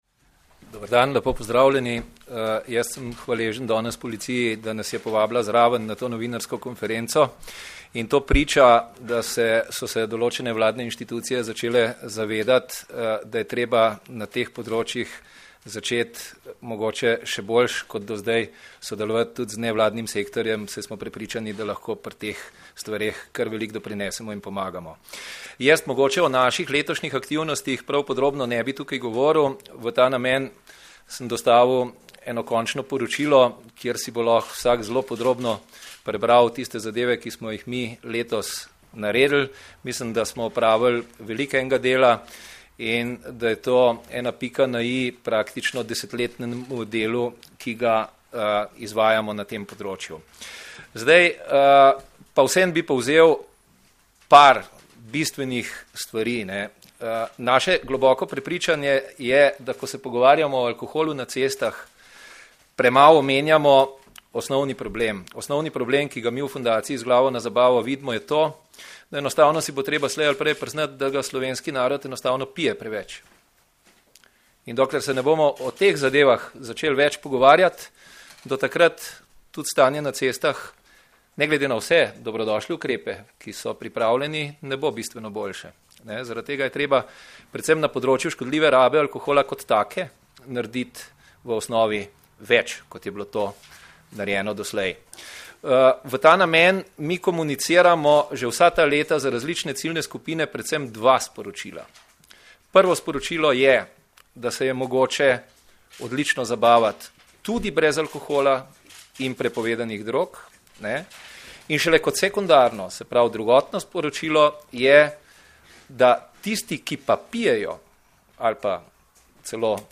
Policija - Alkohol in vožnja v cestnem prometu nista združljiva - informacija z novinarske konference